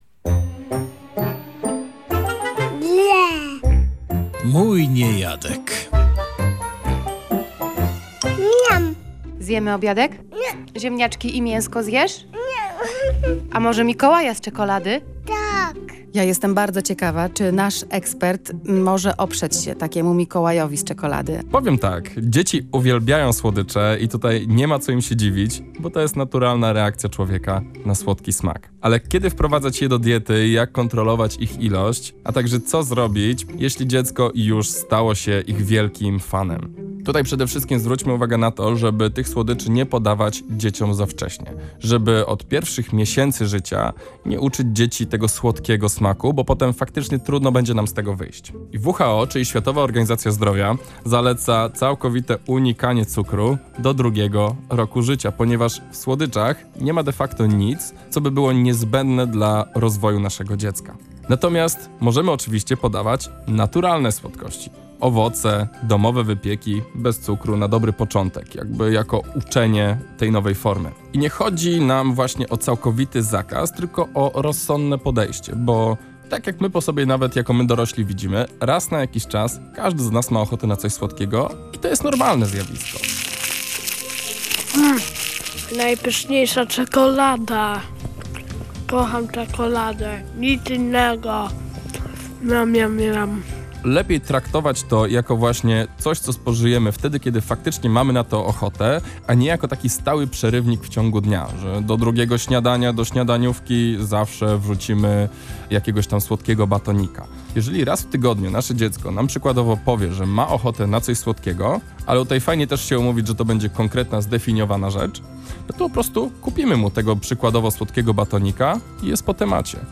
rozmawiała z dietetykiem pediatrycznym